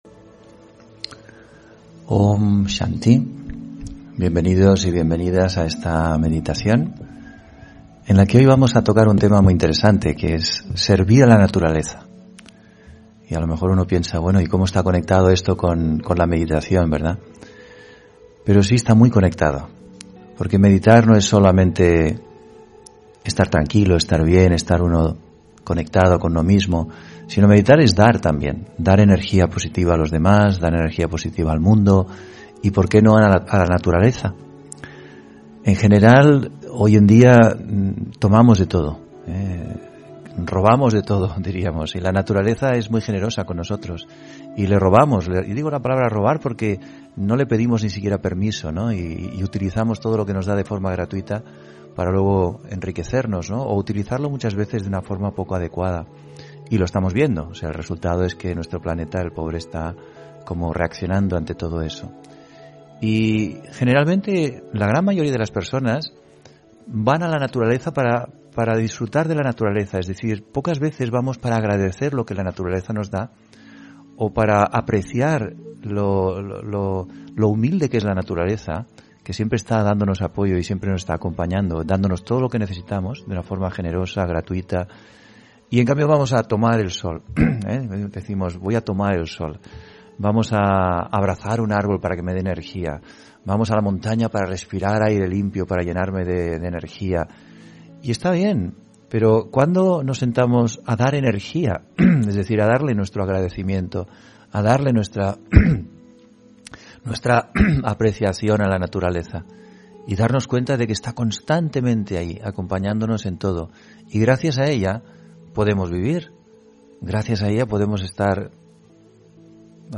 Meditación y conferencia : Servir a la naturaleza (8 Julio 2022)